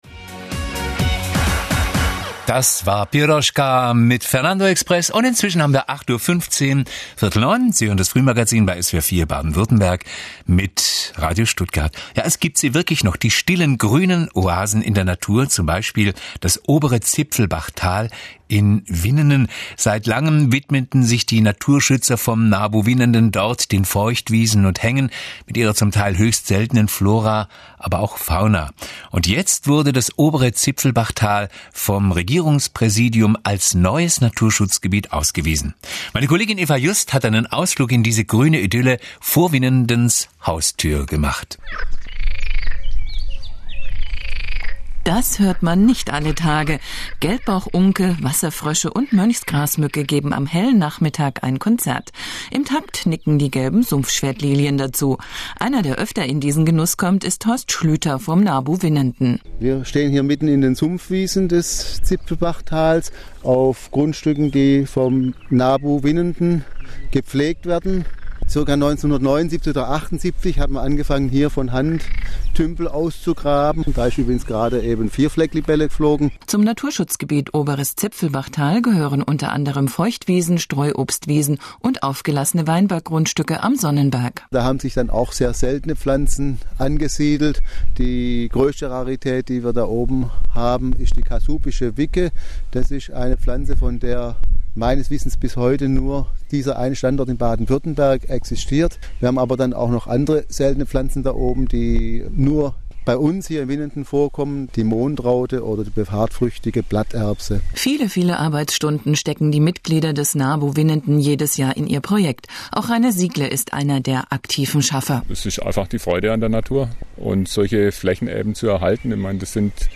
Radiobeitrag von SWR4 am 12.6.2009 (MP3 1,8MB)
Sogar Gelbbauchunken, Teichfr�sche und V�gel schienen von dem Termin erfahren zu haben und machten durch ihre Rufe lauthals mit.